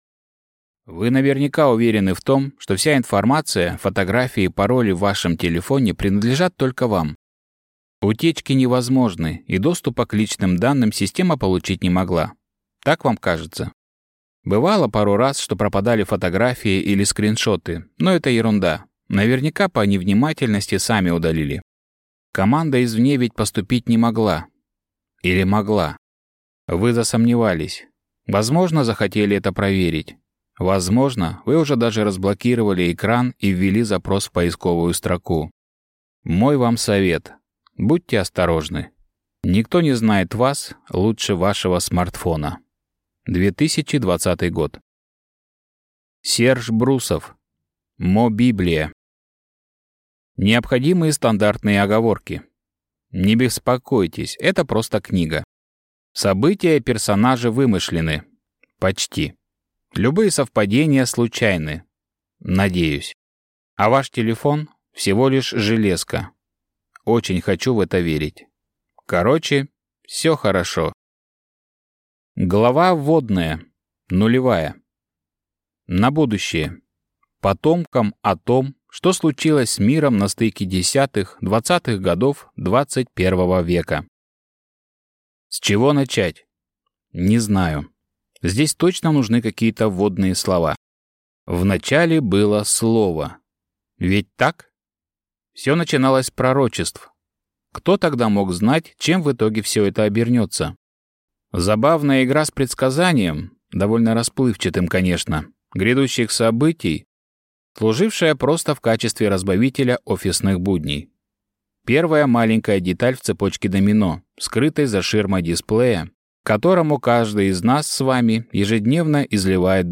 Аудиокнига МоБиблия | Библиотека аудиокниг